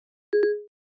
Звук Siri в iPhone когда она не расслышала вопрос или отключилась